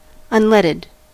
Ääntäminen
Synonyymit lead-free leadless non-leaded Ääntäminen US UK : IPA : /ˌʌnˈlɛd.əd/ Haettu sana löytyi näillä lähdekielillä: englanti Käännös Adjektiivit 1. sin plomo Unleaded on sanan unlead partisiipin perfekti.